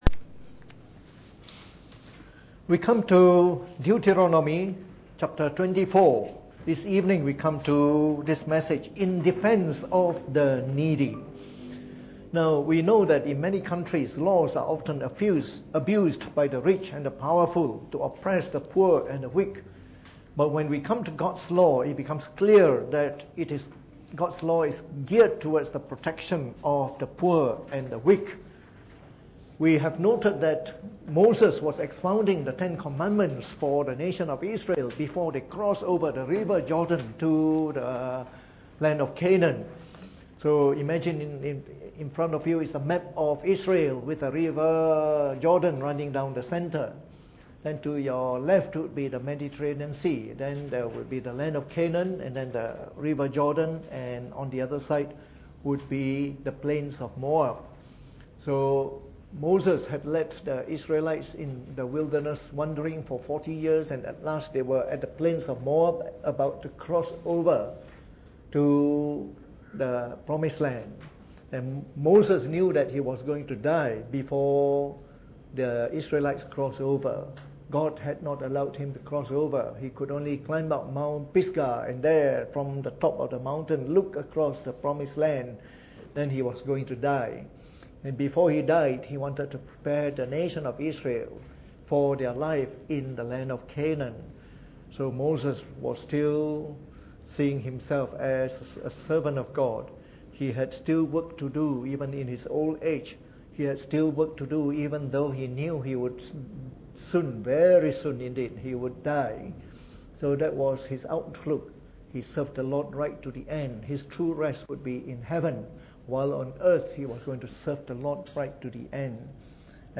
Preached on the 1st of August 2018 during the Bible Study, from our series on the book of Deuteronomy.